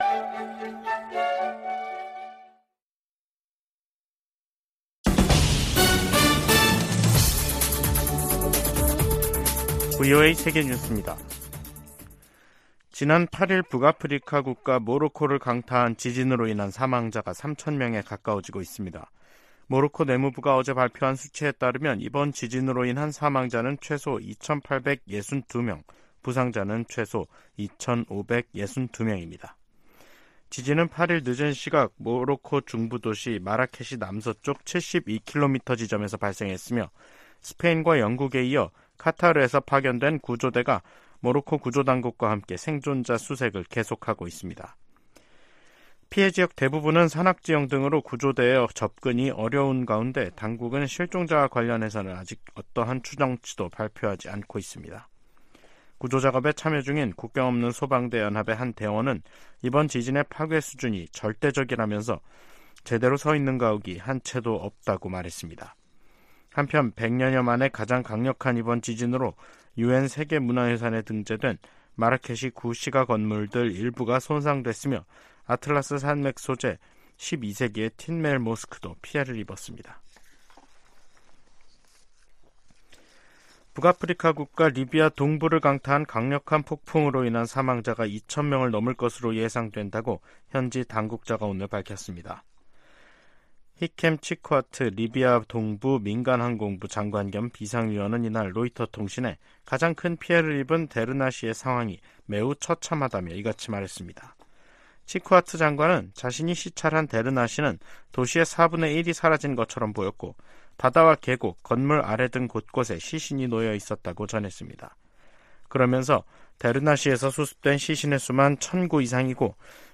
VOA 한국어 간판 뉴스 프로그램 '뉴스 투데이', 2023년 9월 12일 2부 방송입니다. 김정은 북한 국무위원장이 러시아 방문에 군부 실세들을 대동하고 있는 것으로 알려졌습니다. 미국 정부는 북러 정상회담을 면밀히 주시한다고 밝히고 북한이 러시아에 무기를 제공하지 않겠다고 한 약속을 지킬 것을 촉구했습니다. 북한 풍계리에서 추가 핵실험을 지원할 수 있는 활동 징후가 계속 포착되고 있다고 국제원자력기구(IAEA) 사무총장이 밝혔습니다.